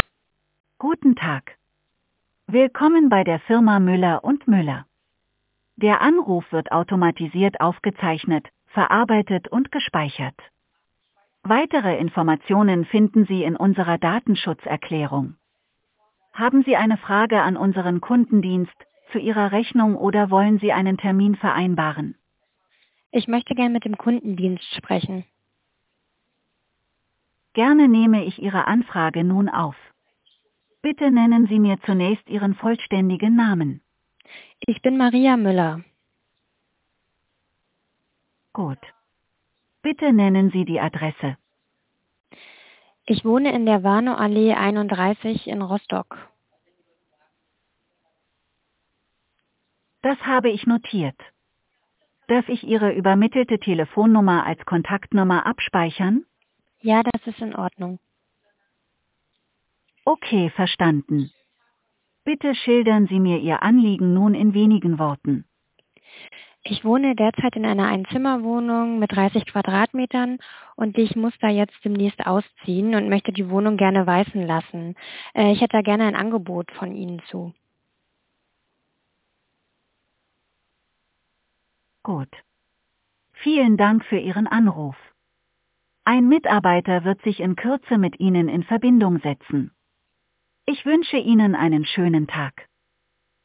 Der intelligente 11880-Sprachbot unterstützt Ihren Handwerksbetrieb rund um die Uhr, an 365 Tagen im Jahr.